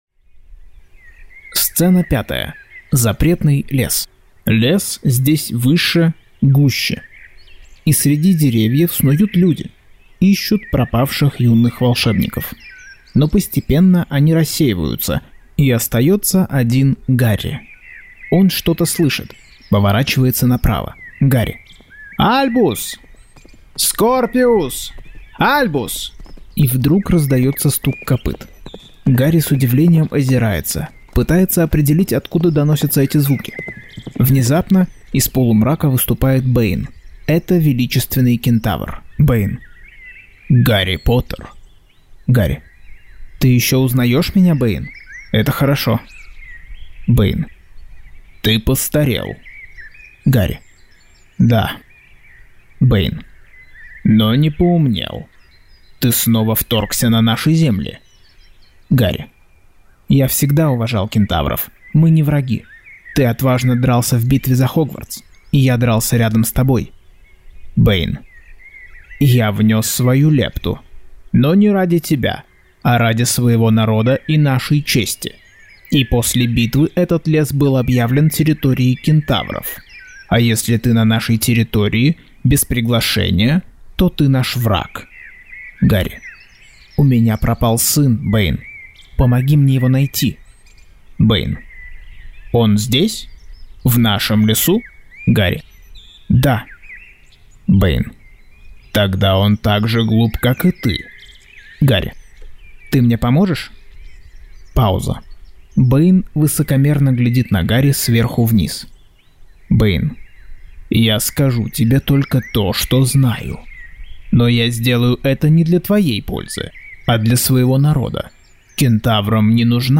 Аудиокнига Гарри Поттер и проклятое дитя. Часть 18.